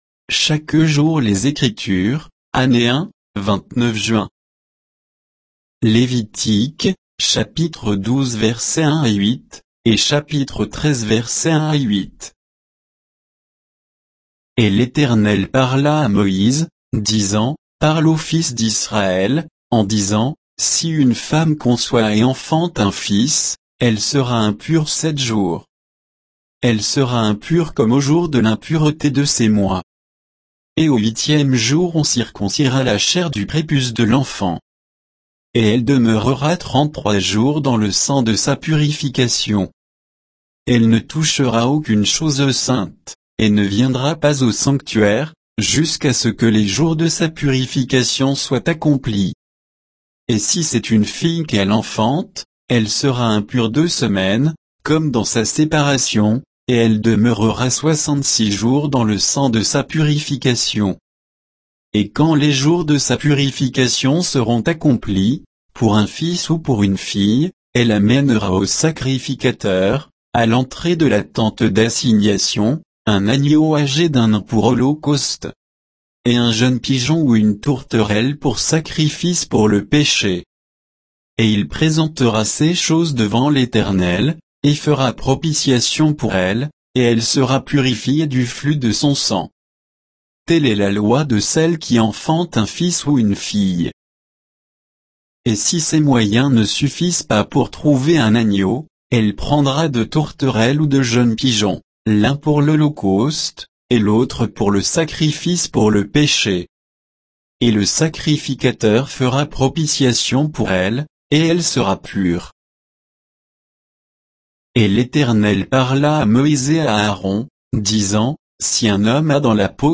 Méditation quoditienne de Chaque jour les Écritures sur Lévitique 12, 1 à 13, 8